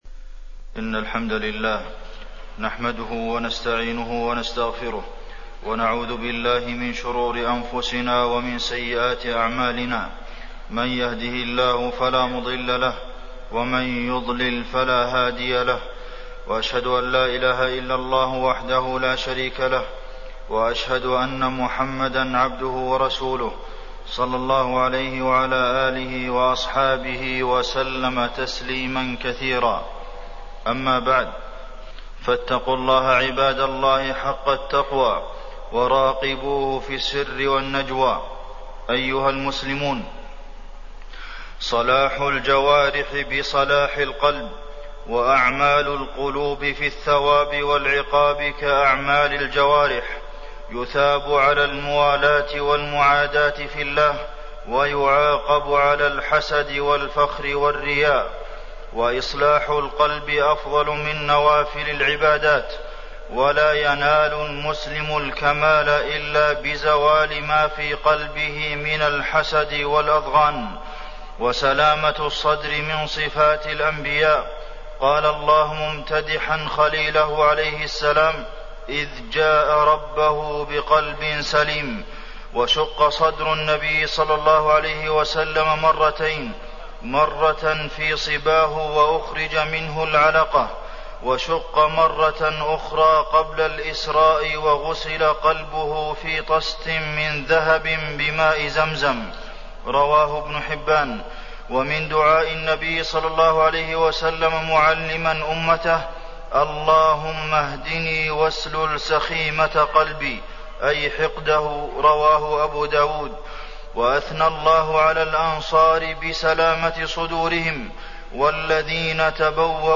تاريخ النشر ٢٥ صفر ١٤٣٠ هـ المكان: المسجد النبوي الشيخ: فضيلة الشيخ د. عبدالمحسن بن محمد القاسم فضيلة الشيخ د. عبدالمحسن بن محمد القاسم الحسد وسلامة الصدر